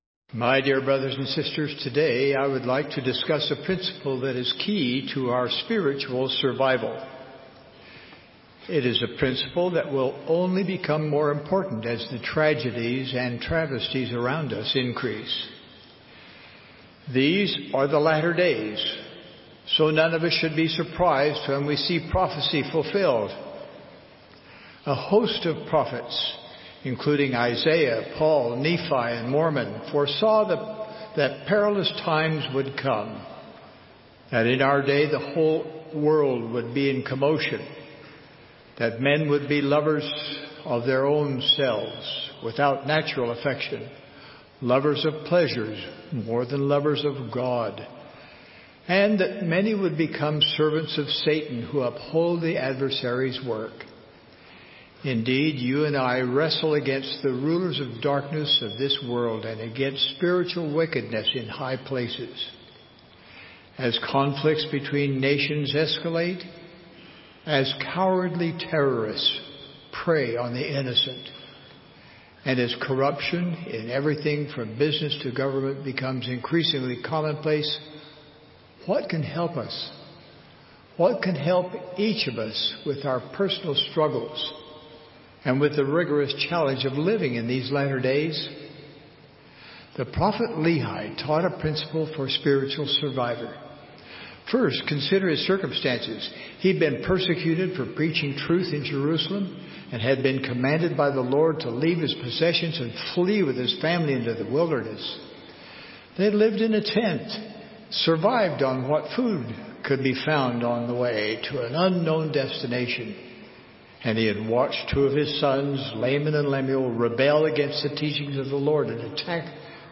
Conference Reports